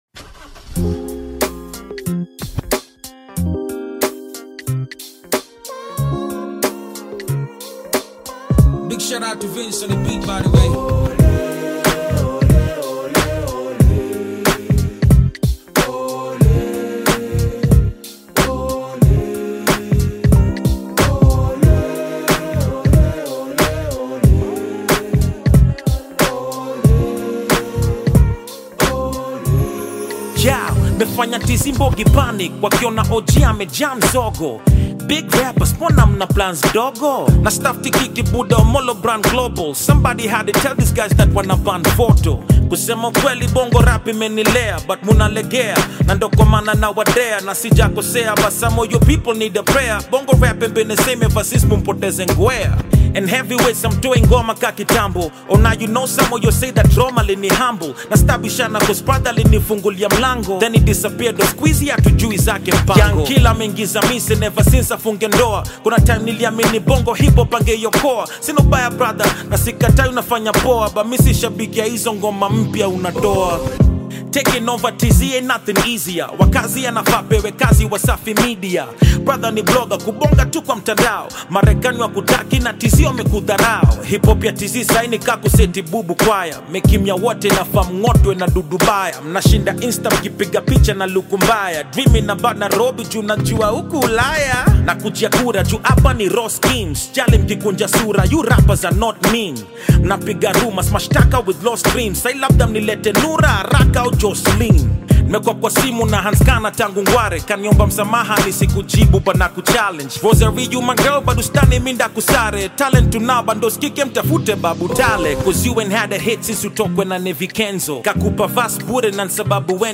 Hip-hop artists dis track